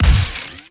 GUNTech_Sci Fi Shotgun Fire_04_SFRMS_SCIWPNS.wav